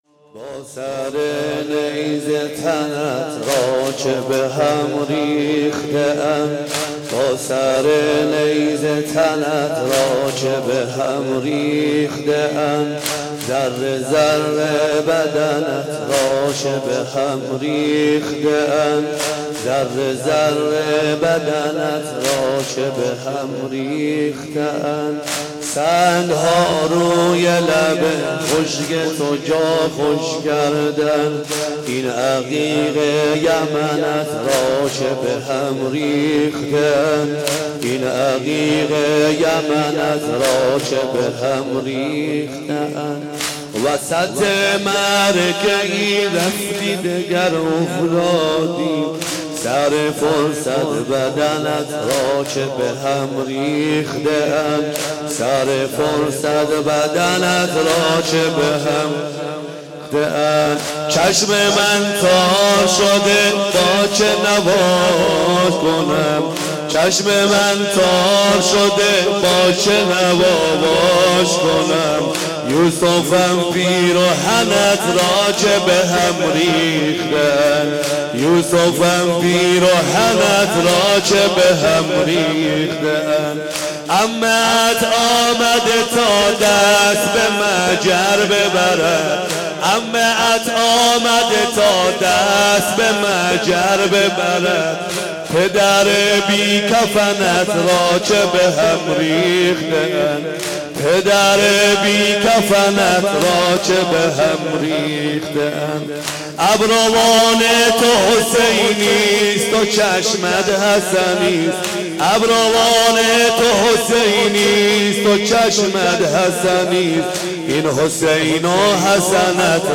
شب ششم محرم 1395